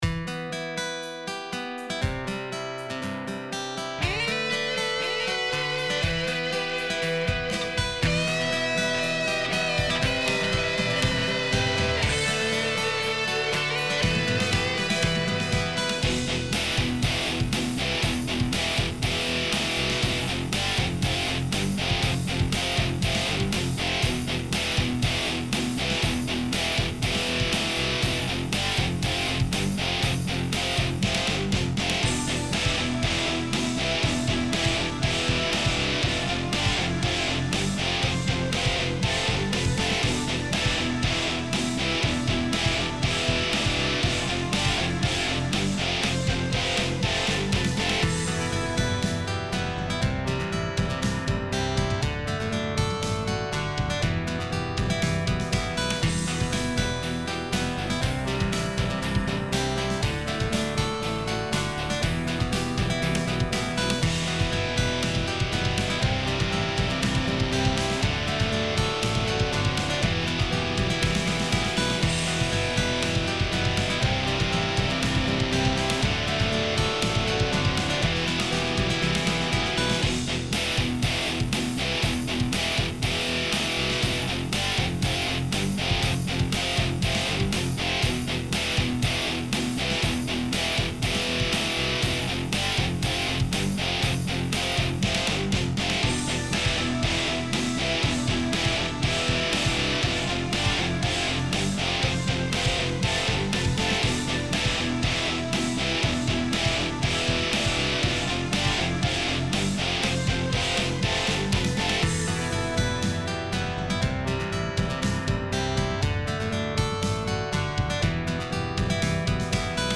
Lead Guitar (Solo) & Drums